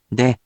We’re going to show you the character, then you you can click the play button to hear QUIZBO™ sound it out for you.
In romaji, 「で」 is transliterated as 「de」which sounds sort of like the English word「day」**